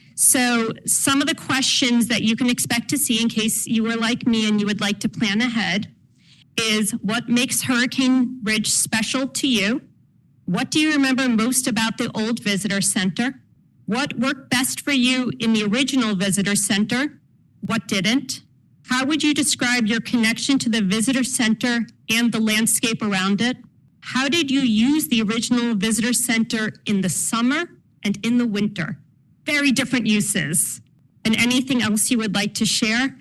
Port Angeles – Olympic National Park Superintendent Sula Jacobs presented an update on the park at Tuesday’s City council meeting, and spoke primarily about what to expect at next week’s free public event at Field Arts and Events Hall in Port Angeles.